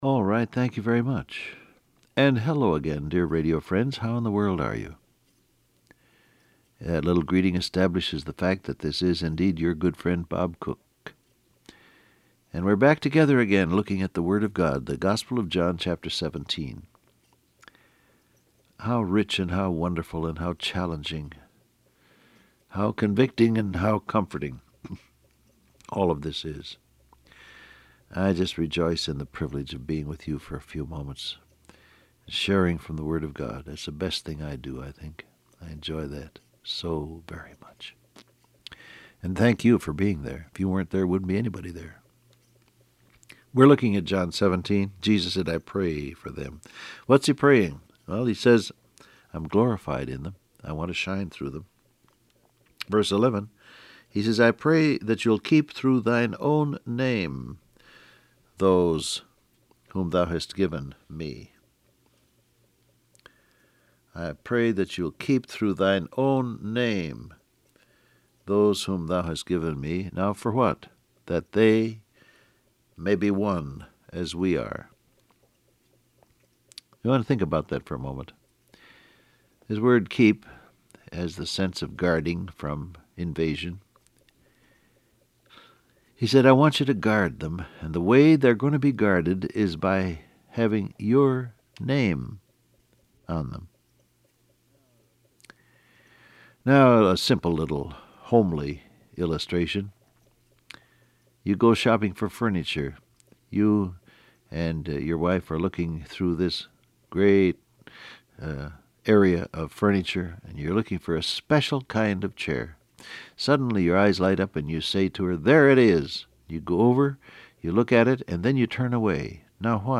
Download Audio Print Broadcast #6950 Scripture: John 17:11 Topics: Fellowship , By Faith , Kept , Identified , Sanctified Transcript Facebook Twitter WhatsApp Alright, thank you very much.